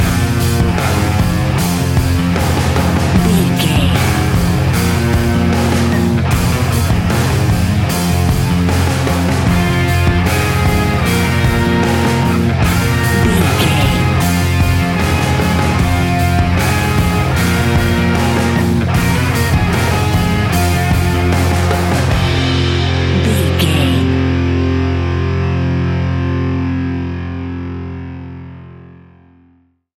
Ionian/Major
F♯
hard rock
heavy metal
distortion
instrumentals